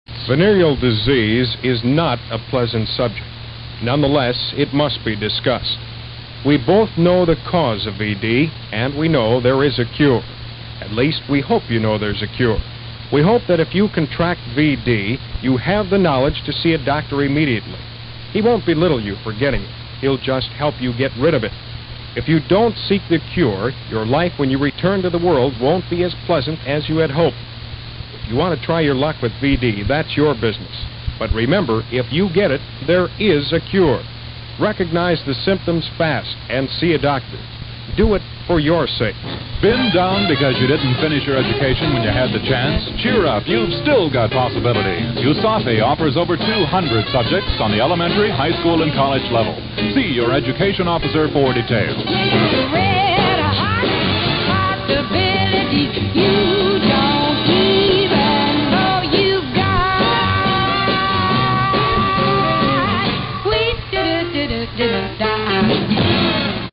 Veneral Disease Education PSAs